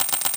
Coin Counter Loop 01.wav